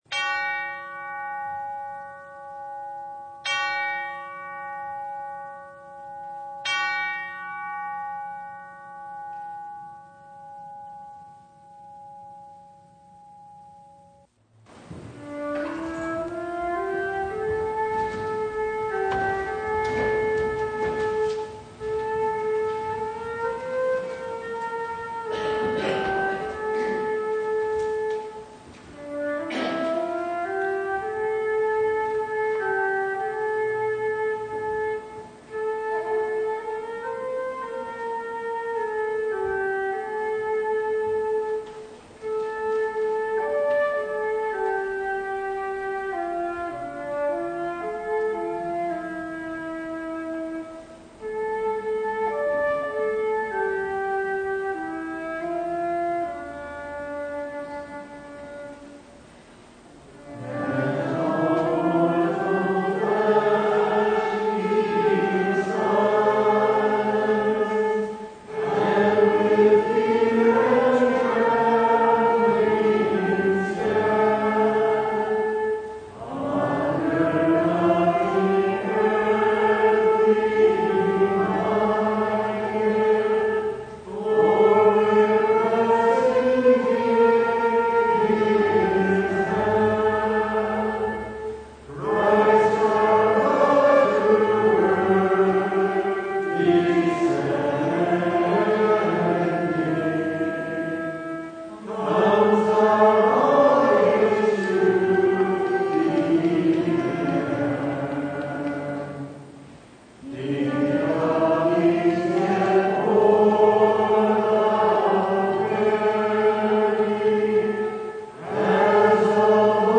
Service Type: Sunday
Download Files Notes Bulletin Topics: Full Service « Saints?